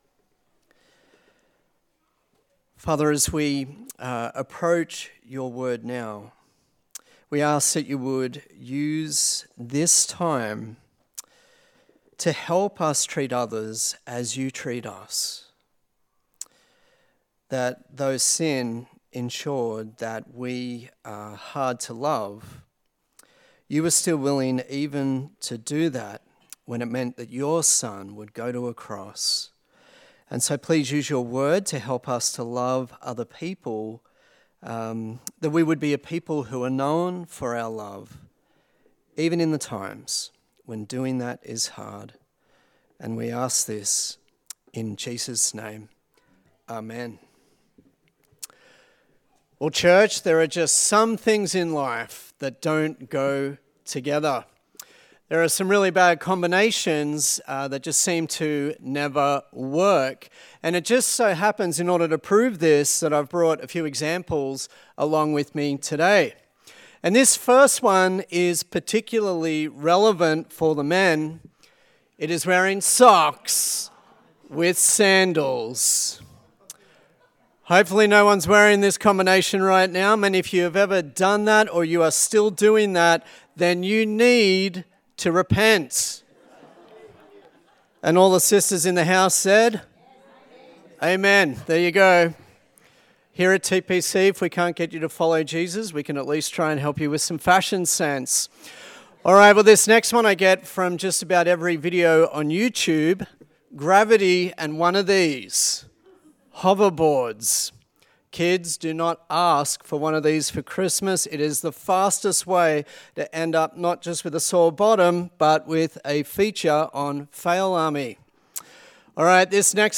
A sermon in the series on the book of James
Service Type: Sunday Service